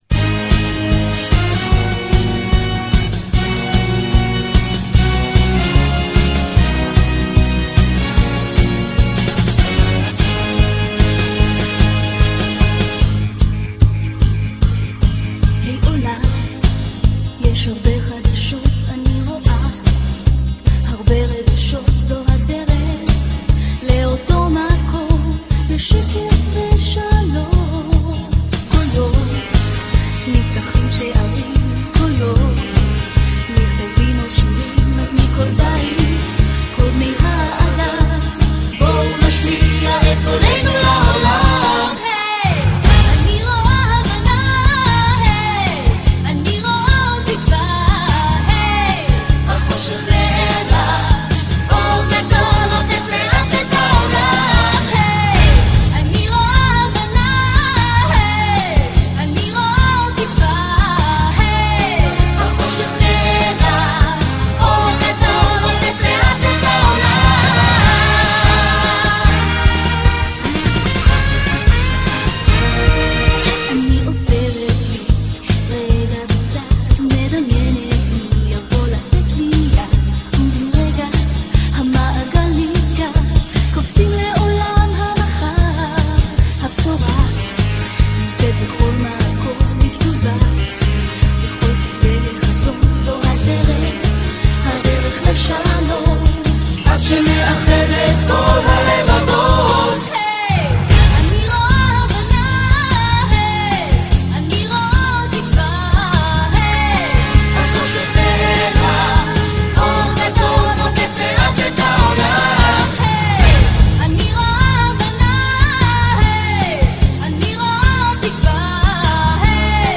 Venue:IBA studios, Jerusalem.
made a very lively and catchy performance